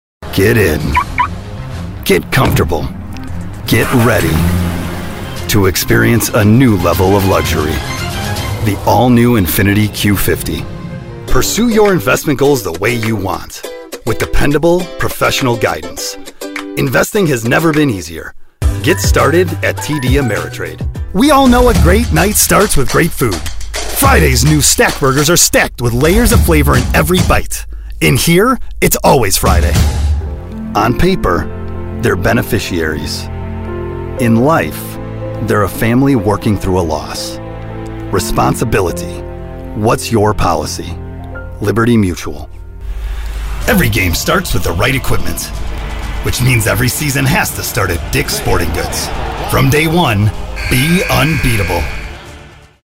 Deep, Powerful Voice! Pro Studio!
Commercial Demo Reel
Midwest American, Southern American
Middle Aged
In need of a deep, powerful, husky voice?